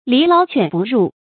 籬牢犬不入 注音： ㄌㄧˊ ㄌㄠˊ ㄑㄨㄢˇ ㄅㄨˋ ㄖㄨˋ 讀音讀法： 意思解釋： 籬笆編得結實，狗就鉆不進來。